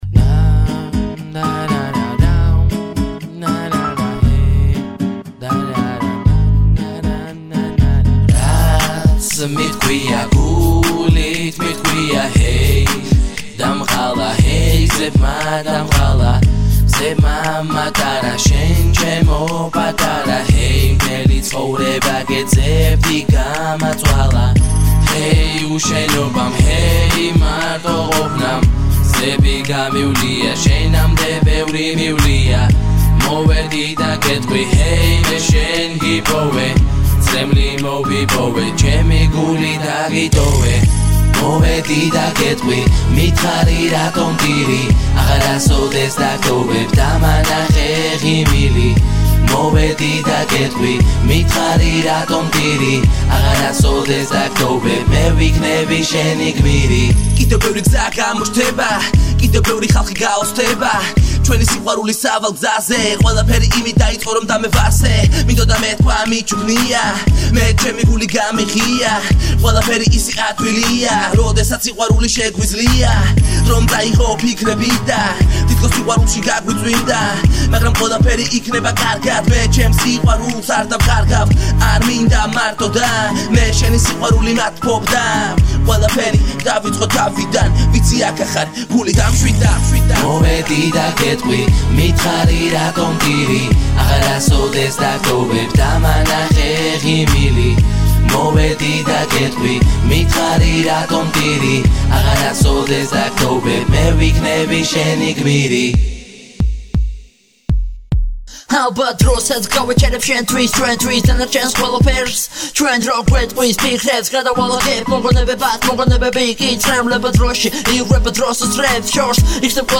კატეგორია: Hip-Hop